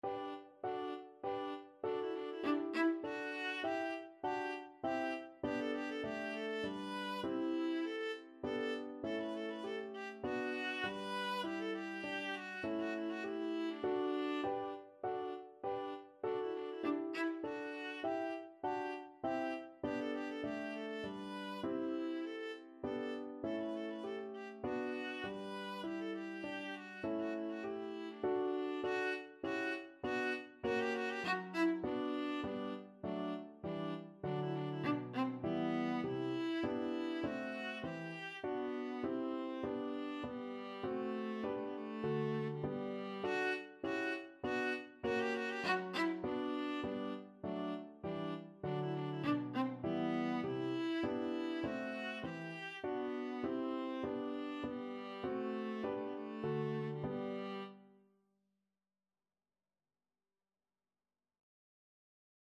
Viola
Allegretto
3/4 (View more 3/4 Music)
G major (Sounding Pitch) (View more G major Music for Viola )
Classical (View more Classical Viola Music)